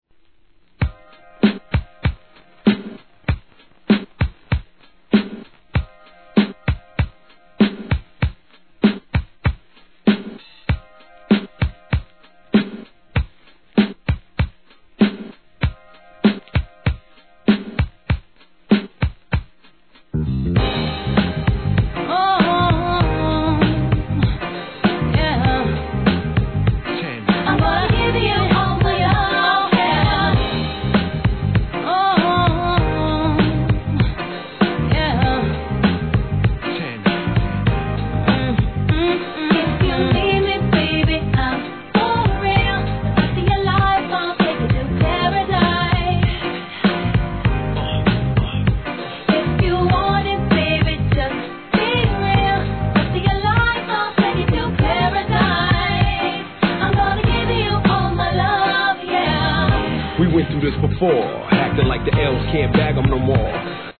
HIP HOP/R&B
DJのために繋ぎ易さも考慮されたREMIX人気シリーズ72番!!